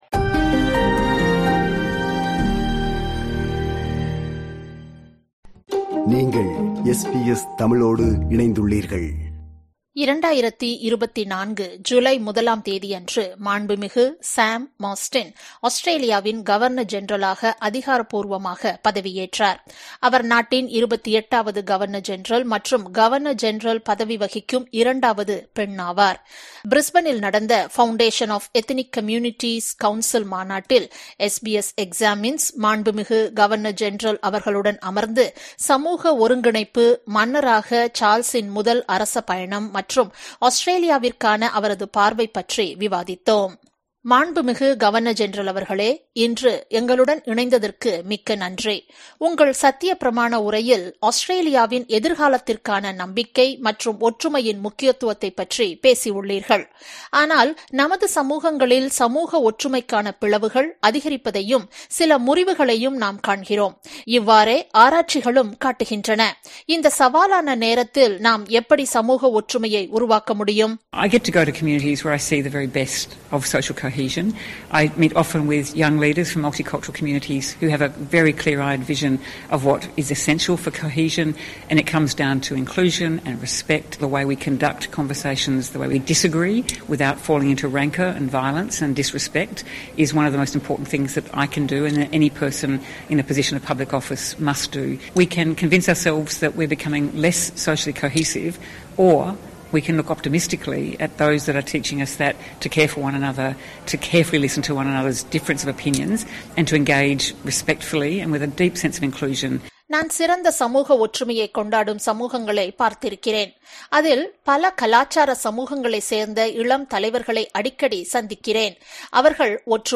SBS Examines உடனான ஒரு நேர்காணலில், மாண்புமிகு Sam Mostyn சமூக ஒற்றுமை, நம்பிக்கை மற்றும் முடியாட்சியின் பங்கு பற்றிய தனது எண்ணங்களைப் பகிர்ந்து கொண்டார்.